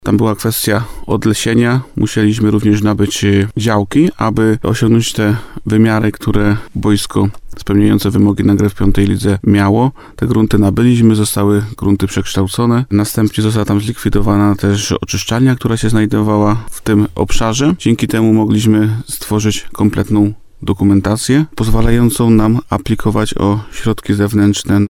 W Przyszowej powstanie stadion z pełnowymiarowym boiskiem do piłki nożnej. – Tutejszy klub będzie grać na sztucznej nawierzchni – zapowiada wójt gminy Łukowica, Bogdan Łuczkowski.